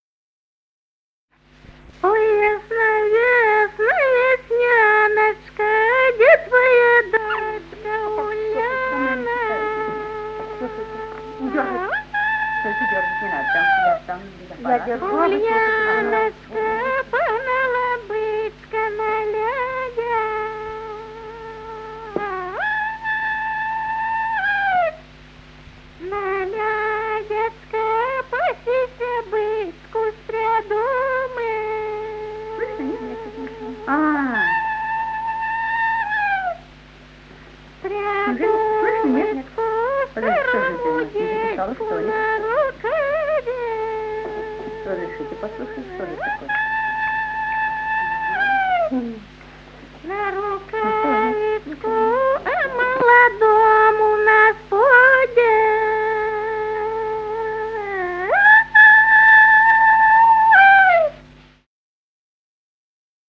с. Курковичи.